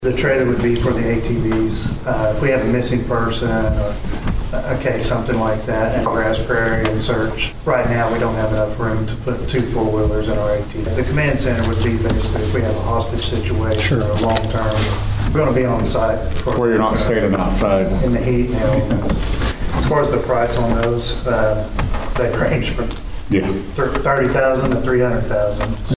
At Monday's Board of Osage County Commissioners meeting, there was discussion regarding the possibility of using ARPA funds to pay for a couple of different items that the Sheriff's Office needs.